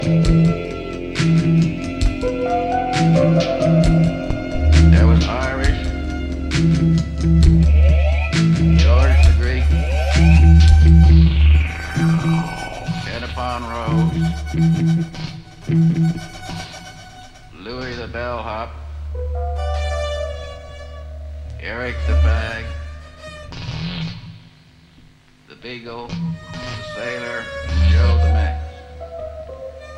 alien ambient music with a monstrous grin.
goose music with a predatory undertow. They are a 6 piece.
Vocals
Guitar
Bass Guitar
Theremin, Tapes, Keyboard
Drums.